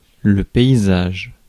Prononciation
France: IPA: [pɛ.i.zaʒ]